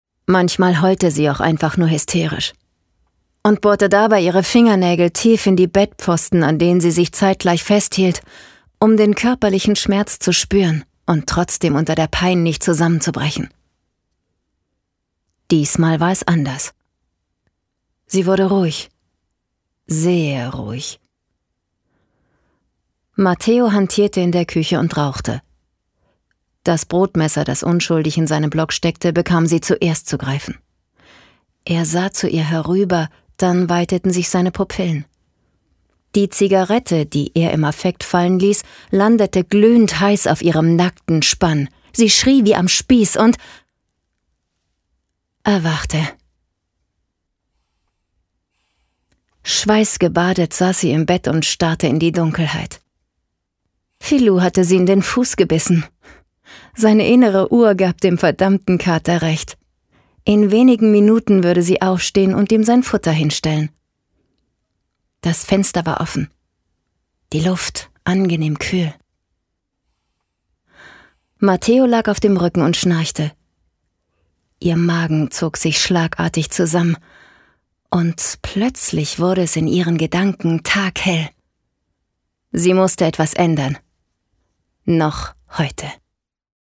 Weiblich
Hörbücher
Mikrofon: Neumann TLM 103
Professionelle akustische Aufnahmekabine